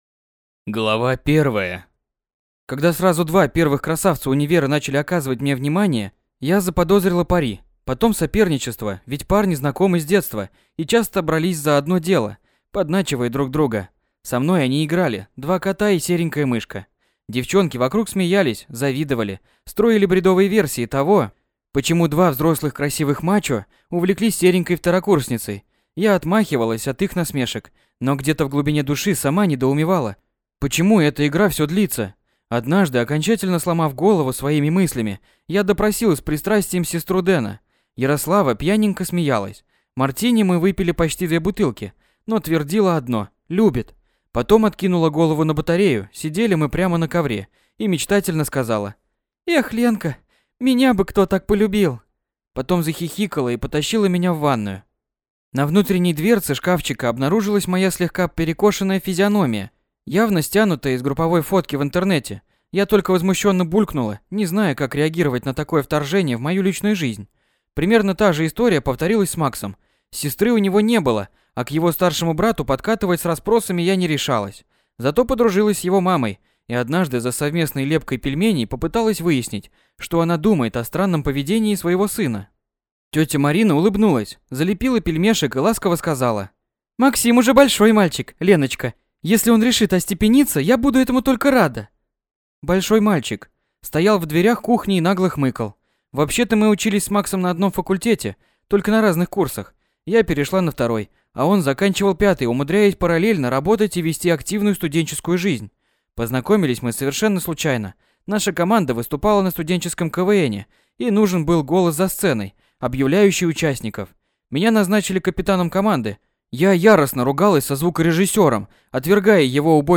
Аудиокнига Недетские игры | Библиотека аудиокниг